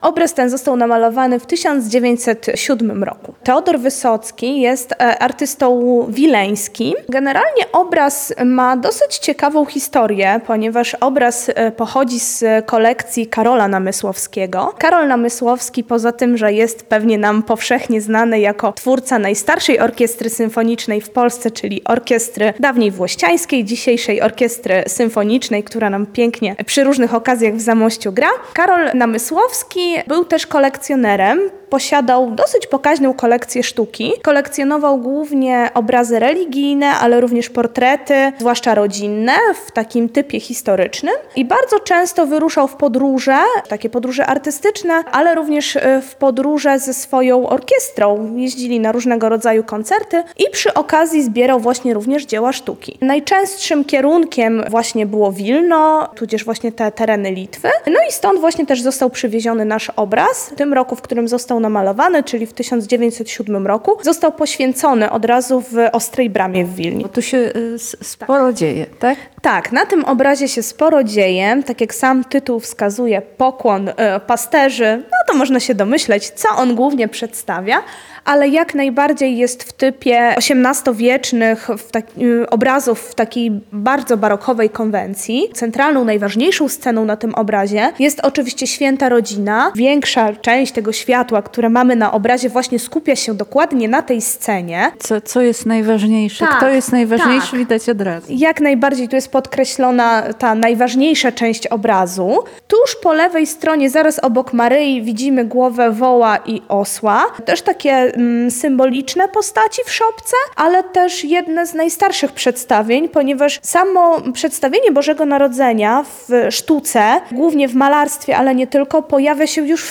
Cała rozmowa w materiale dźwiękowym: